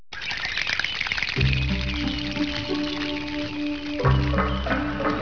Kodama are the cute little white, half-transparent sprites that live in healthy forests. They make the funniest noises.
kodamasclick.wav